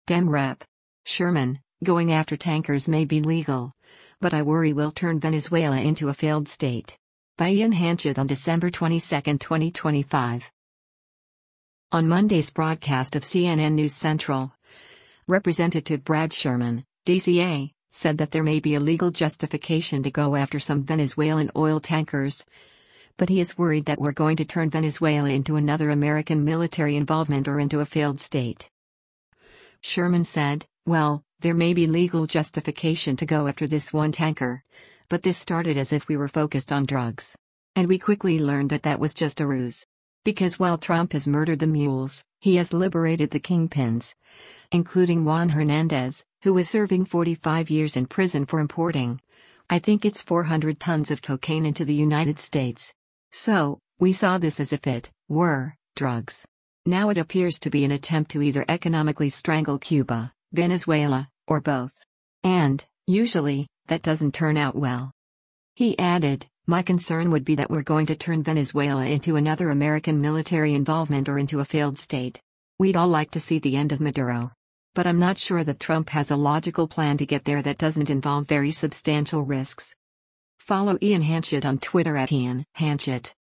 On Monday’s broadcast of “CNN News Central,” Rep. Brad Sherman (D-CA) said that there may be a legal justification to go after some Venezuelan oil tankers, but he is worried “that we’re going to turn Venezuela into another American military involvement or into a failed state.”